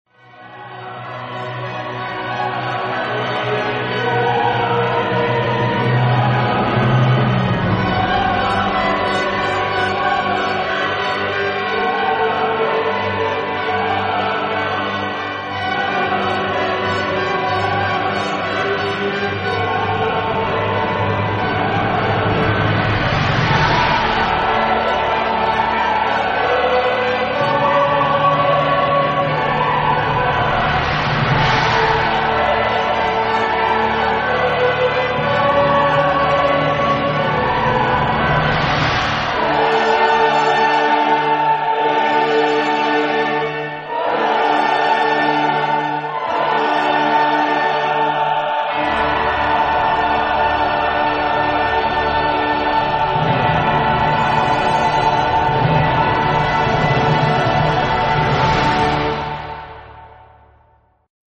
Epoque: 20th century
Genre-Style-Form: Psalm ; Sacred
Mood of the piece: ceremonial ; festive ; contrapuntal ; homophonic
Type of Choir: SSSAAATTTBBB + unison  (13 mixed + children voices )
Instrumentation: Orchestra
Instruments: Transverse flute (4) ; Oboe (3) ; English horn (1) ; Clarinet (3) ; Bassoon (3) ; Contrabassoon (1) ; Horn (4) ; Trumpets (4) ; Trombone (3) ; Tubas (2) ; Timpani (1) ; Percussion (2) ; Celesta (1) ; Piano (1) ; Harp (2) ; Strings